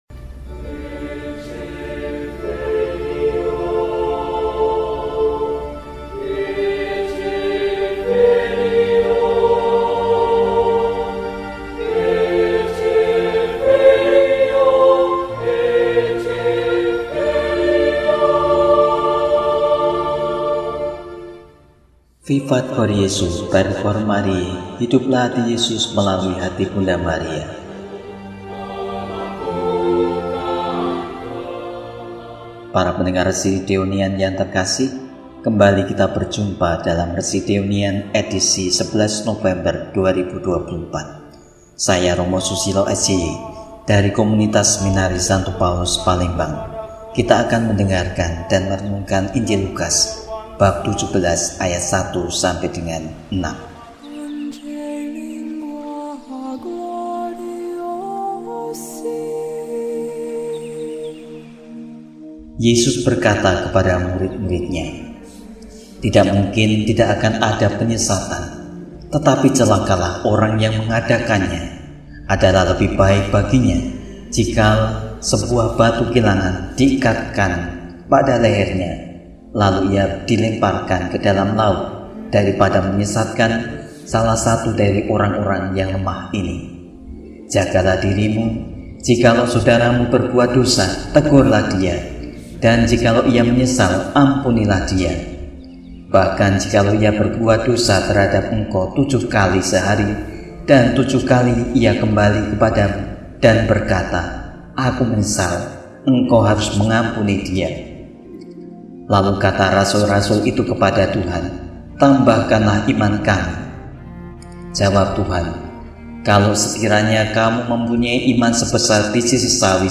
Senin, 11 November 2024 – Peringatan Wajib St. Martinus dari Tours, Uskup – RESI (Renungan Singkat) DEHONIAN